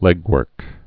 (lĕgwûrk)